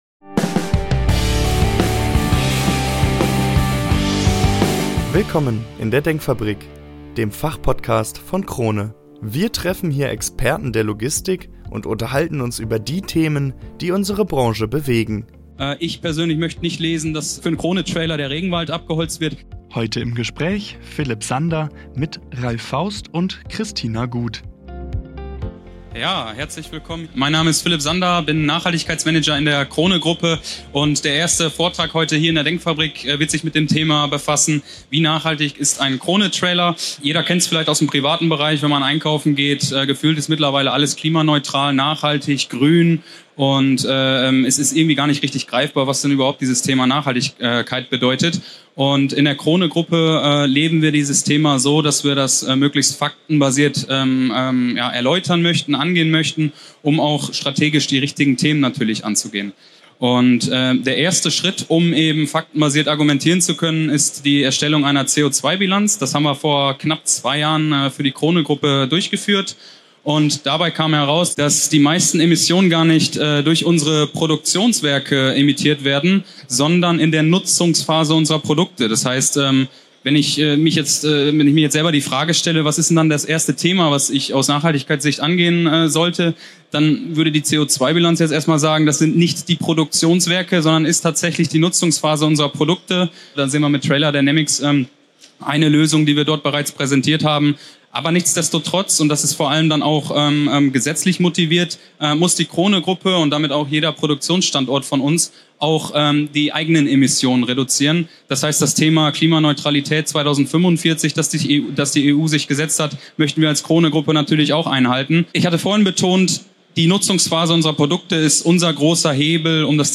Live-Talk aus der DENKFABRIK: Nachhaltigkeit im Fokus In dieser Live-Aufnahme der DENKFABRIK beleuchten wir die wichtigsten Themen der Logistikbranche: Fakten zur CO₂-Bilanz von Trailern  Die Rolle von Recycling und Kreislaufwirtschaft Partne...